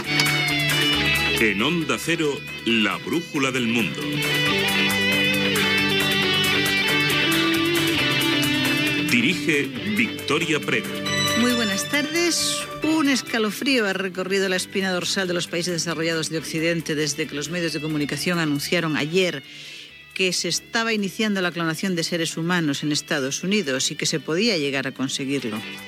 Careta i inici del programa
Informatiu